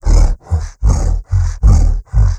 MONSTERS_CREATURES
MONSTER_Exhausted_14_loop_mono.wav